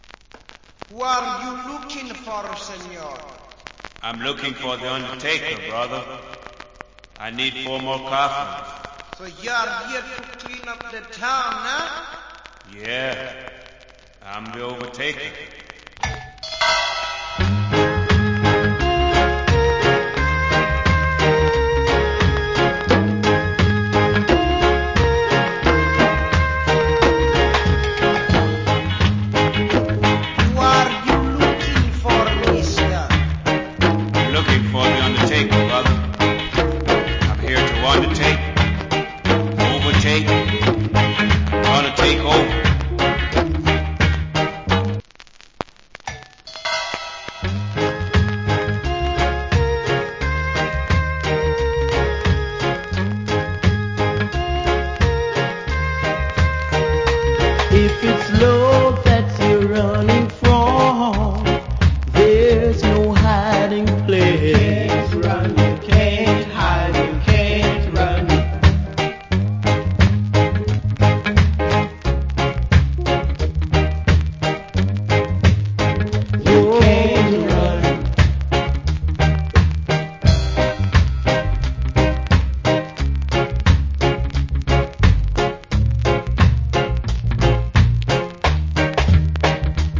Nice Inst + Drums.